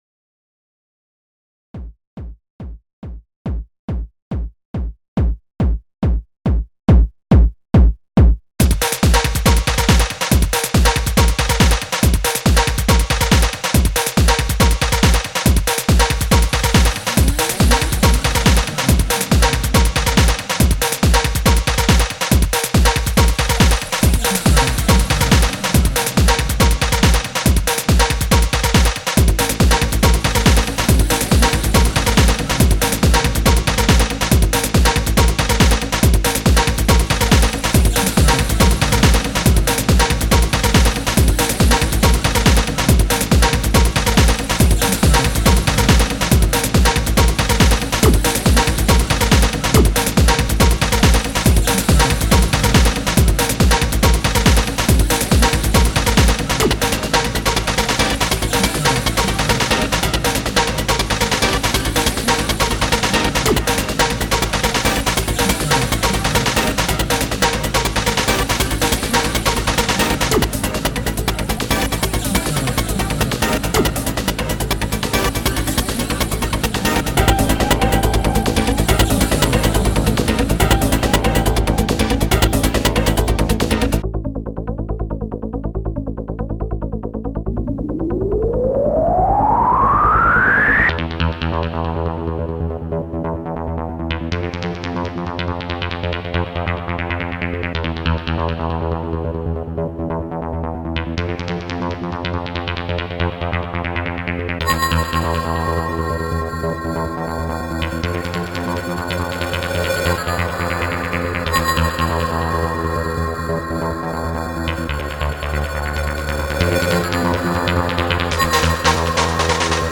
2006 Электронная